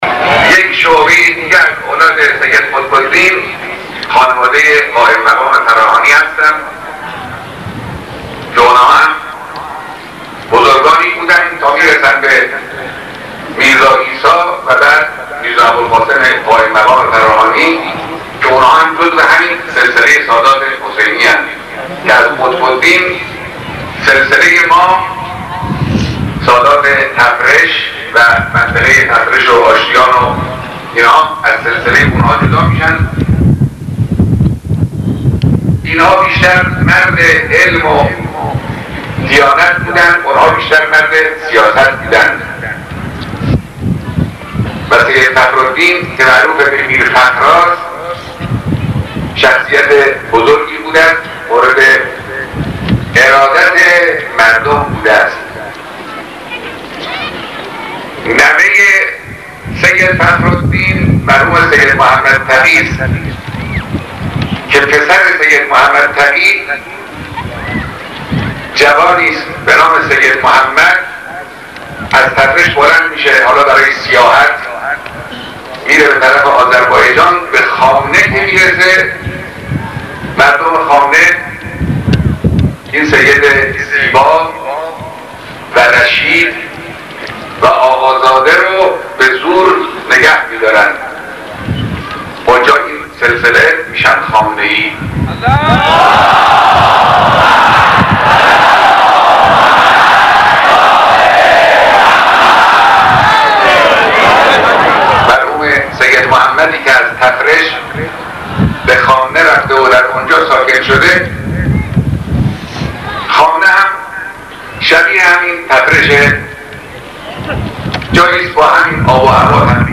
سخنرانی مقام معظم رهبری در تفرش سال 1379